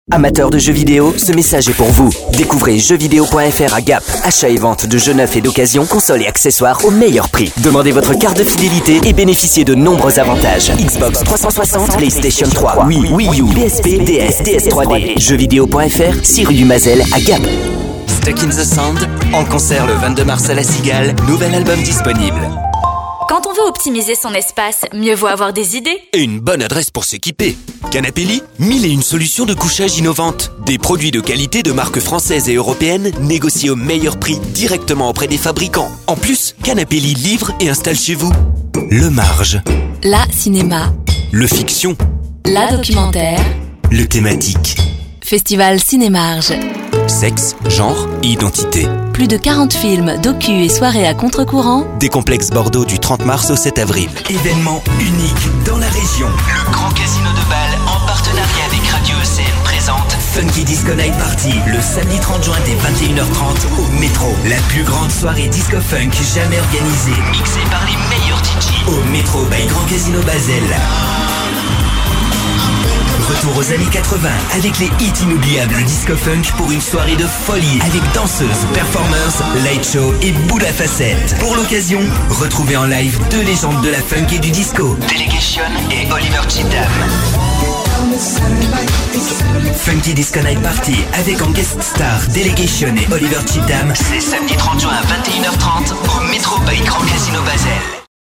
Une voix : médium, au grain atypique, au timbre chaleureux, malléable et adaptable à tous vos projets Un équipement professionnel : microphone Neumann TLM 103, Préampli SPL GoldMike MK II, cabine de prise de son de 10 m3 intégralement traitée acoustiquement.
Le timbre de ma voix est médium, jeune.
Sprechprobe: Werbung (Muttersprache):
french voice actor, medium voice